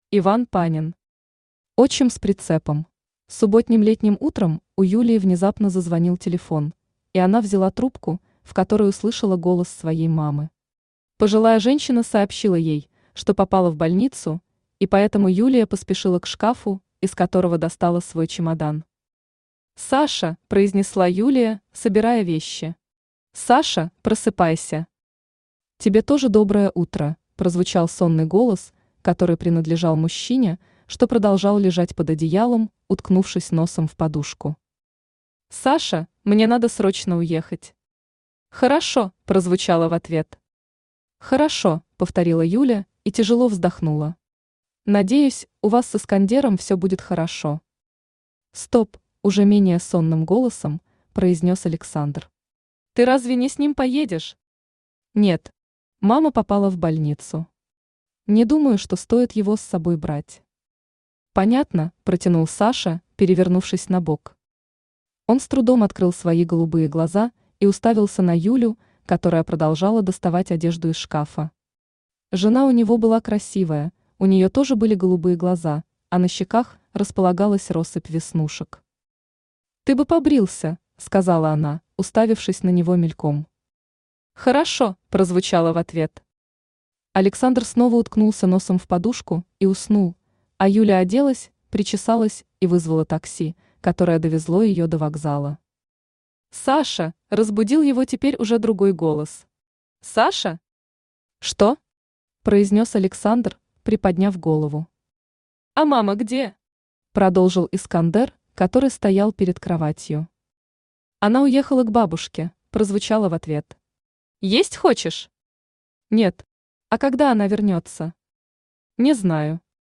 Аудиокнига Отчим с прицепом | Библиотека аудиокниг
Aудиокнига Отчим с прицепом Автор Иван Панин Читает аудиокнигу Авточтец ЛитРес.